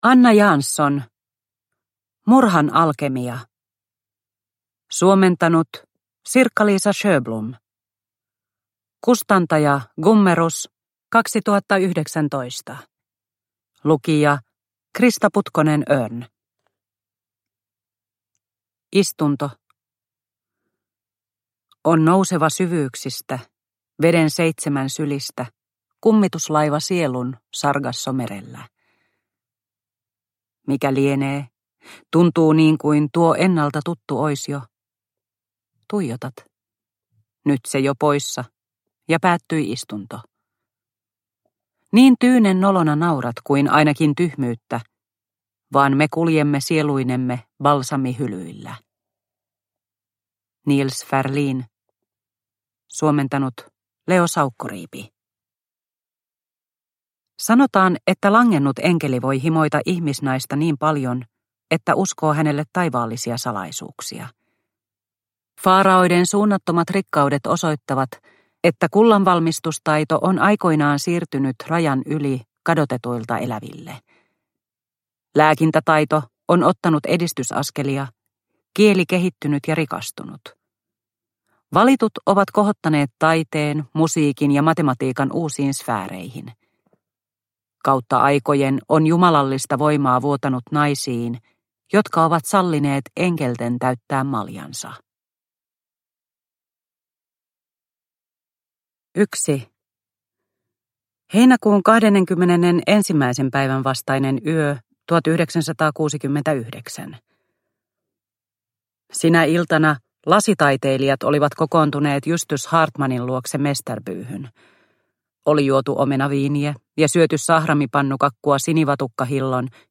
Murhan alkemia – Ljudbok – Laddas ner